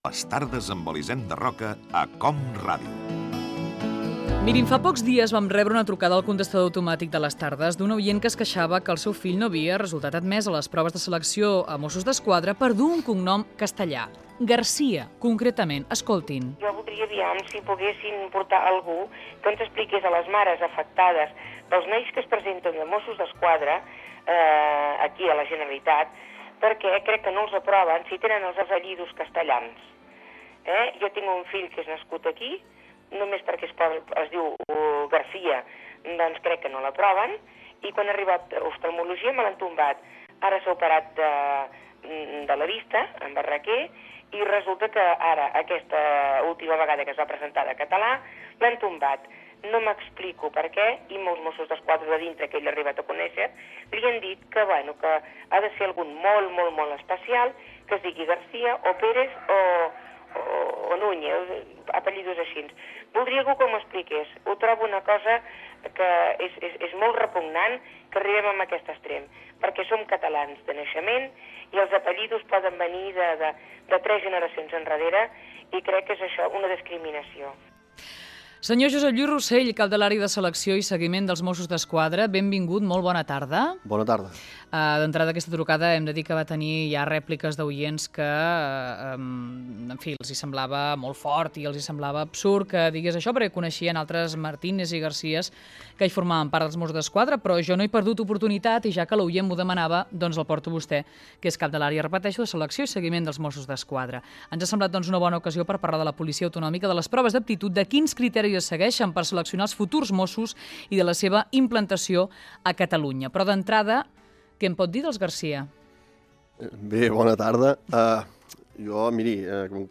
Indicatiu del programa, trucada d'una oient sobre la no admissió pel llinatge García del seu fill als Mosssos d'Esquadra.
Entreteniment